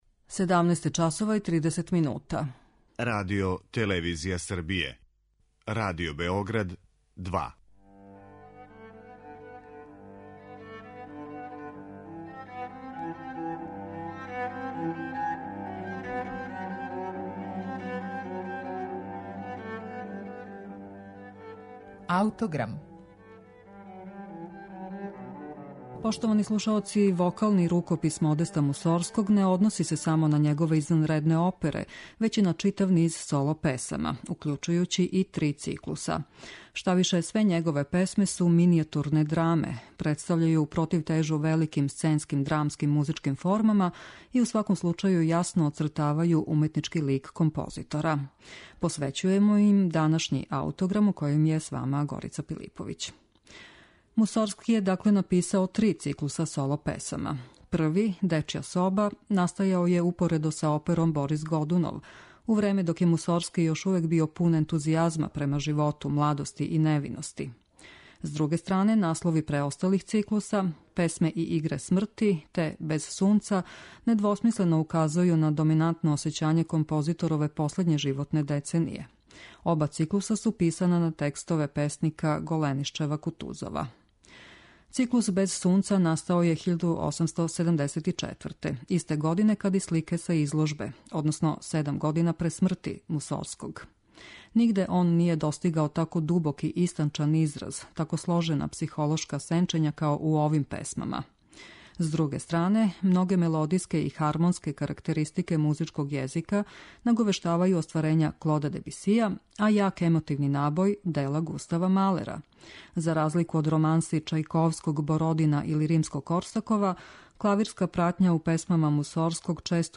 Соло-песме Модеста Мусоргског
Модест Мусоргски је један од највећих руских композитора. Његов вокални опус чине његове величанствене опере, али се исти дух може пронаћи и у циклусима соло-песама, које ће испунити данашњи Аутограм у изванредној интерпретацији Мирослава Чангаловића.